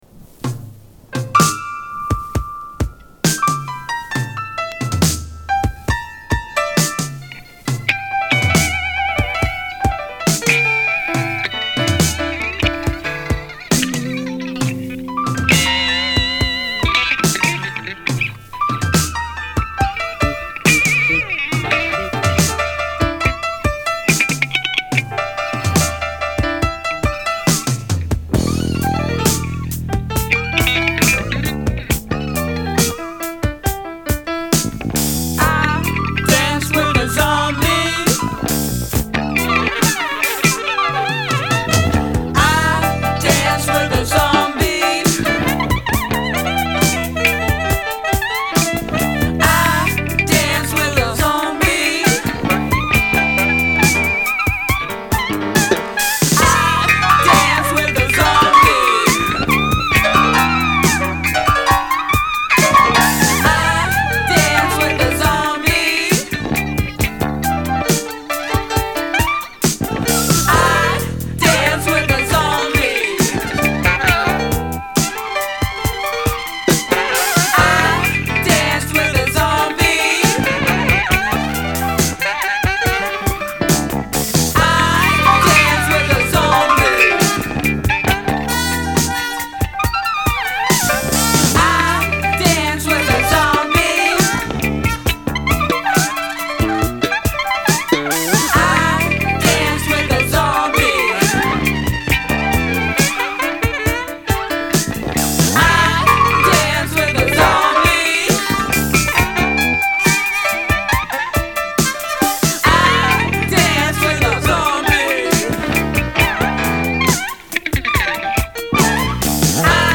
Issu d'un vinyle, son peut-être moyen.